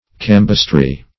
Meaning of cambistry. cambistry synonyms, pronunciation, spelling and more from Free Dictionary.
Cambistry \Cam"bist*ry\, n. The science of exchange, weight, measures, etc.